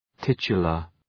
{‘tıtʃələr}
titular.mp3